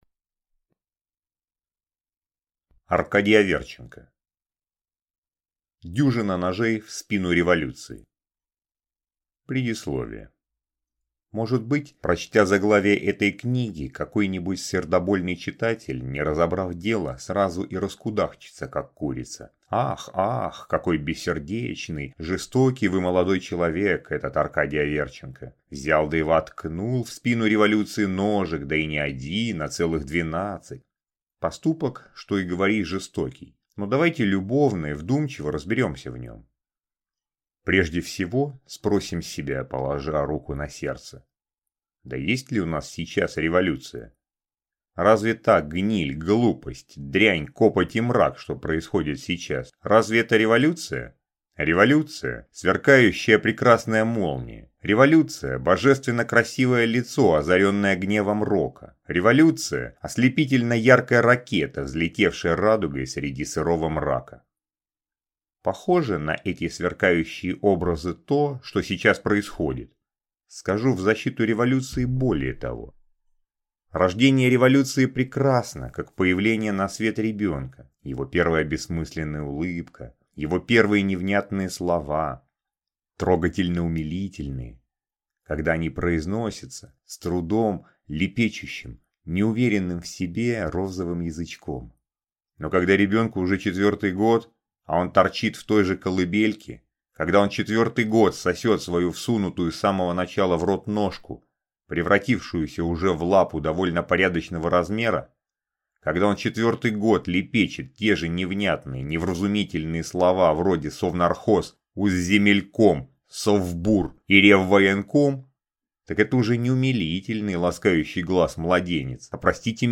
Аудиокнига Дюжина ножей в спину революции | Библиотека аудиокниг